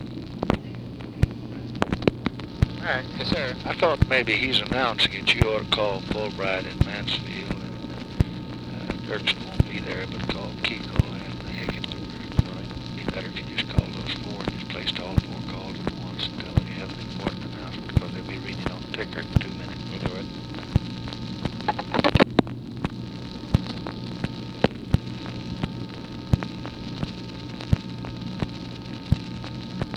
Conversation with MCGEORGE BUNDY, July 8, 1965
Secret White House Tapes